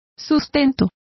Complete with pronunciation of the translation of subsistence.